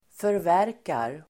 Uttal: [förv'är:kar]